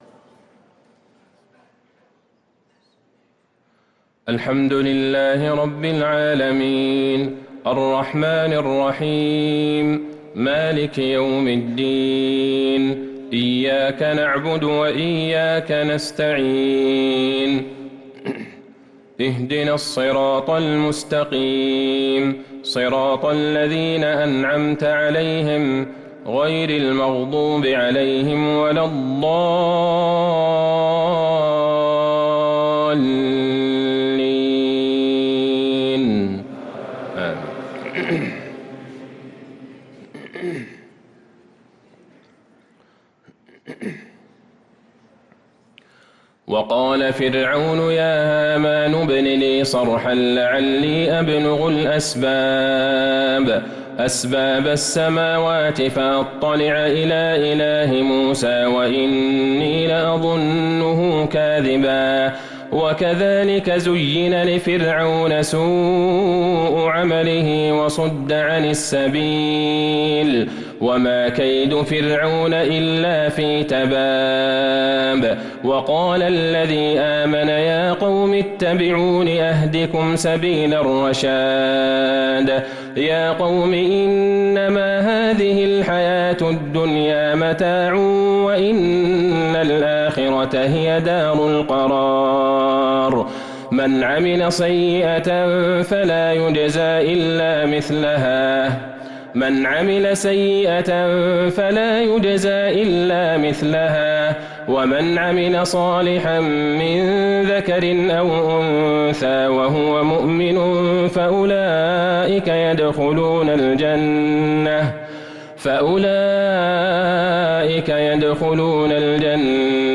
صلاة العشاء للقارئ عبدالله البعيجان 4 جمادي الآخر 1444 هـ
تِلَاوَات الْحَرَمَيْن .